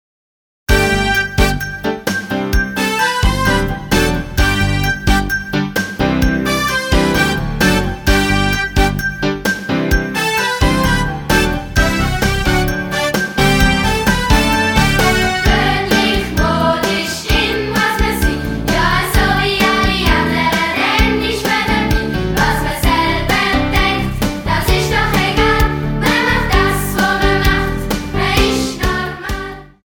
Musical - CD mit Download-Code